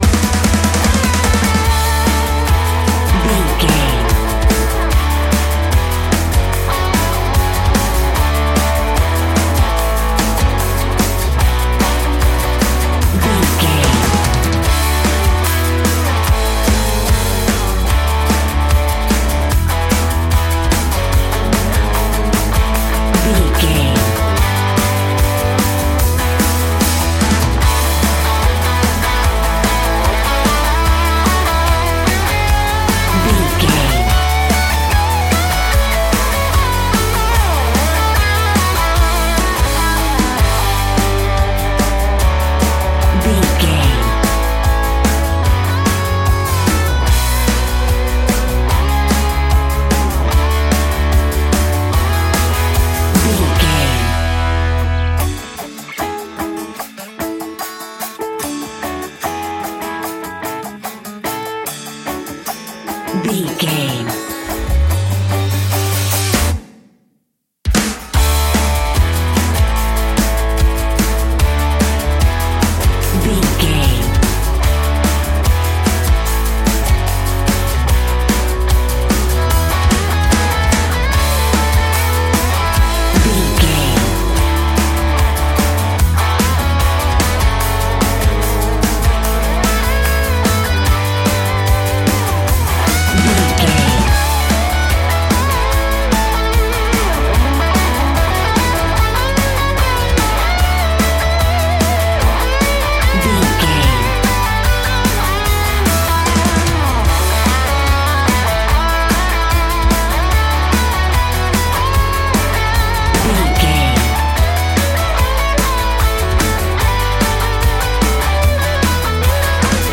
Ionian/Major
Fast
drums
electric guitar
bass guitar
Pop Country
country rock
bluegrass
uplifting
driving
high energy